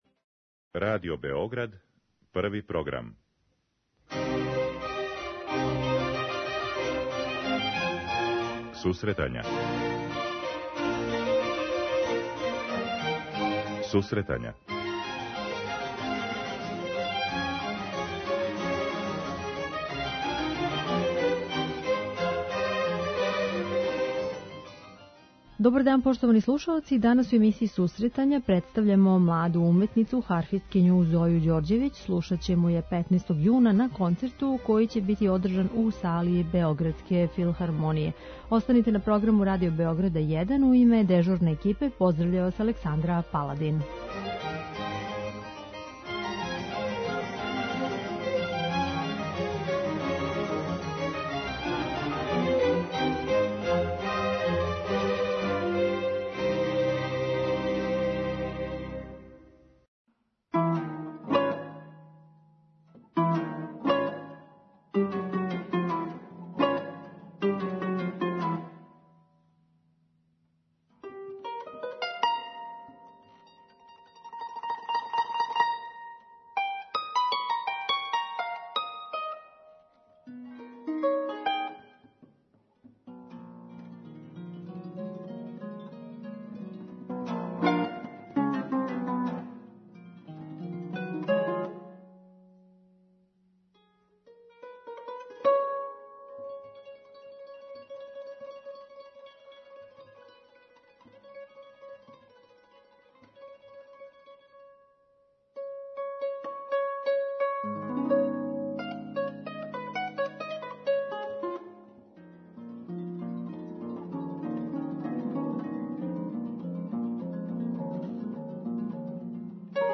О концерту, наступима, харфи, жељама, перспективама и недоумицама данас у емисији разговарамо са овом младом уметницом.